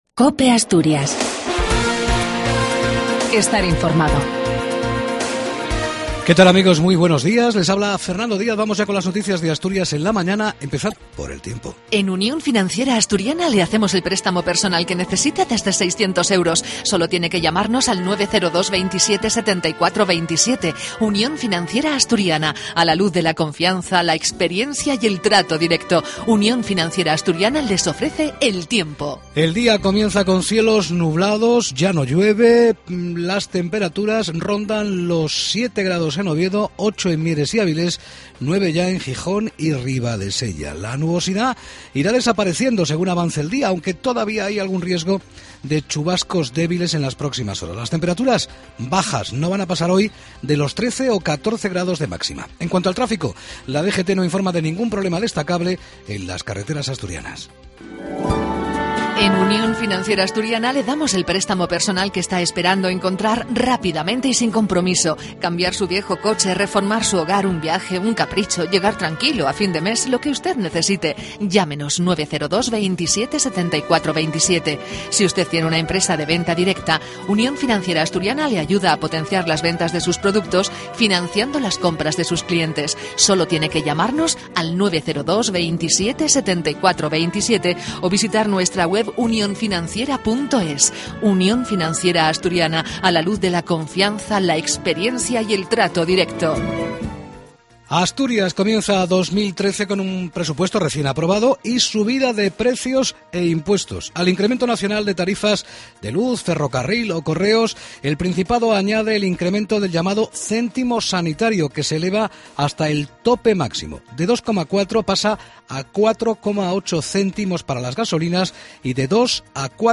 La Mañana Informativos Asturias